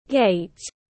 Gate /ɡeɪt/